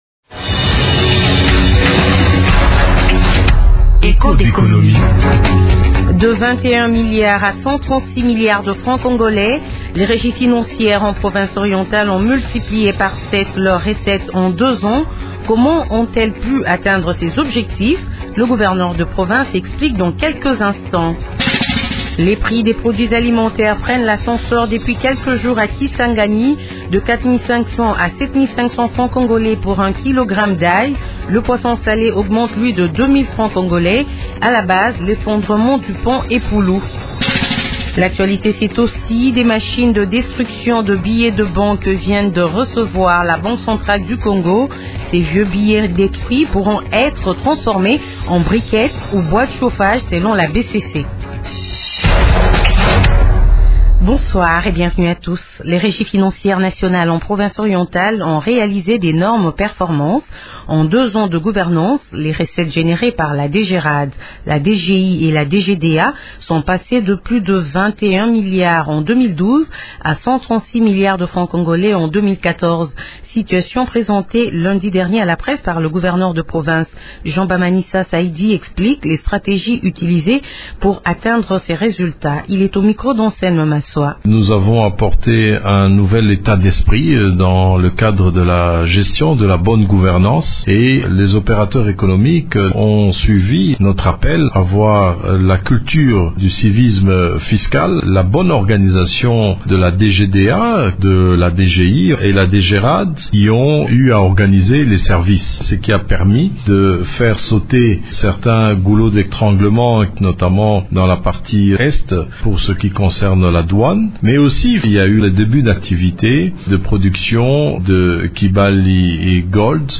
Le gouverneur de province fournit des explications au cours de cette émission.